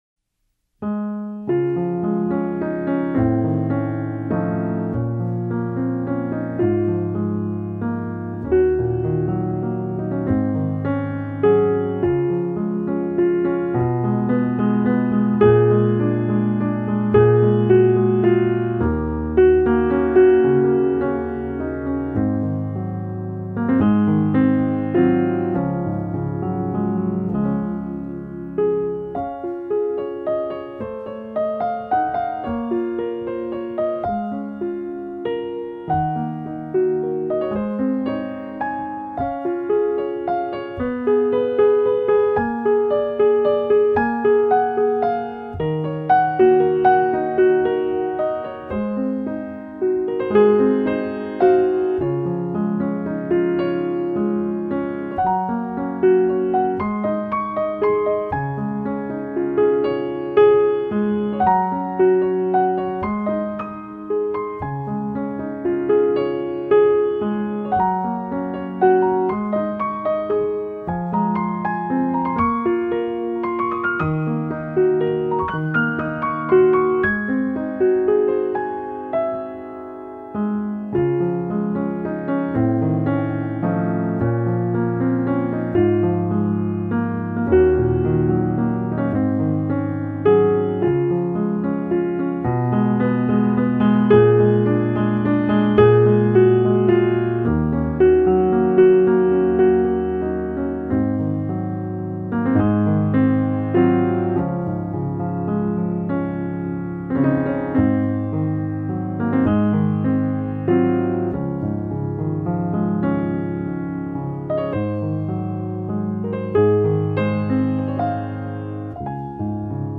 鋼琴
部分曲子加入弦樂、吉他、手風琴等樂器，呈現更豐富的音樂氛圍。
用最溫柔、平和的曲調表現出來。